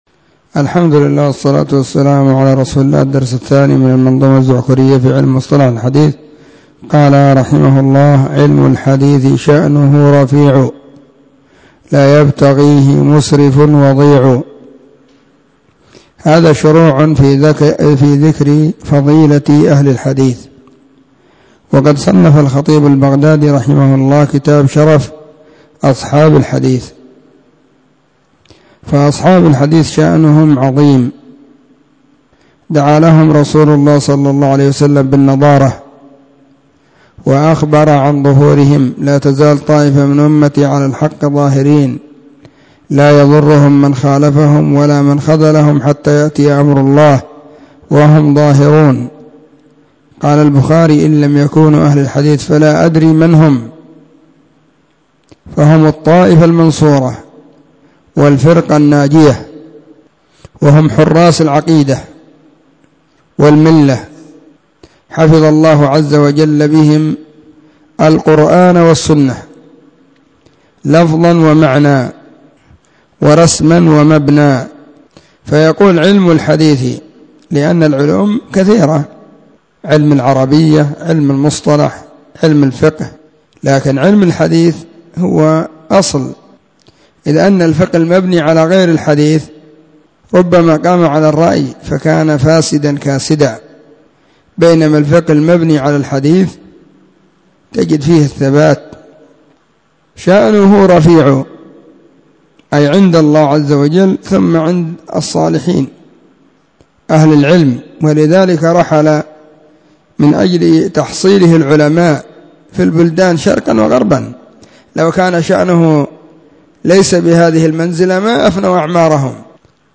مسجد الصحابة – بالغيضة – المهرة، اليمن حرسها الله.